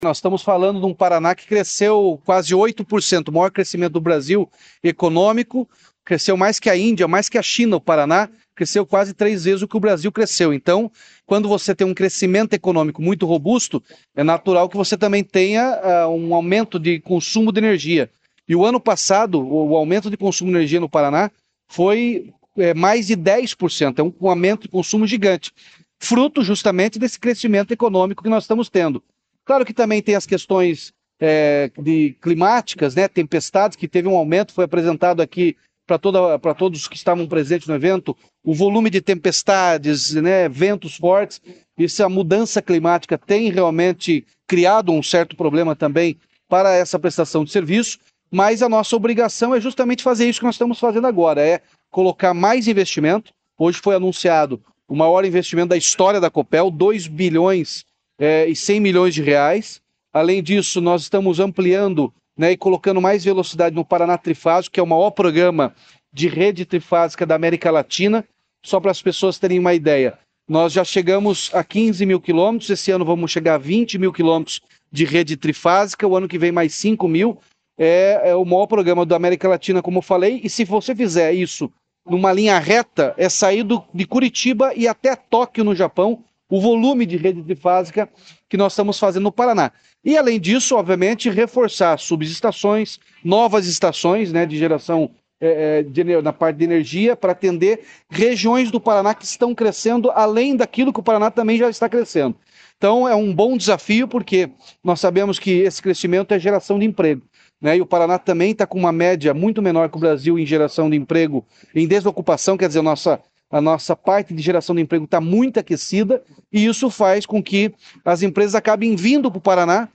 Sonora do governador Ratinho Junior sobre os investimentos anunciados pela Copel para 2024